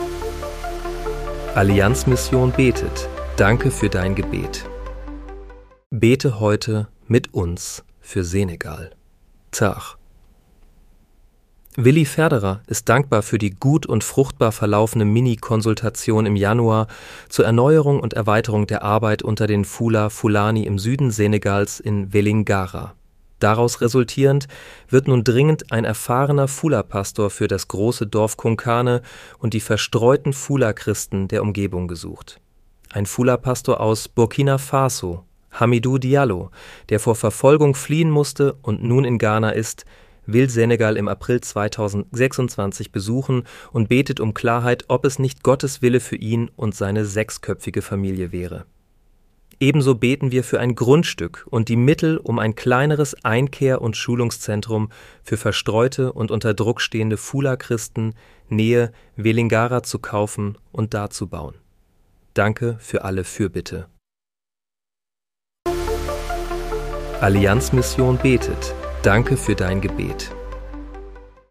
Bete am 03. April 2026 mit uns für Senegal. (KI-generiert mit der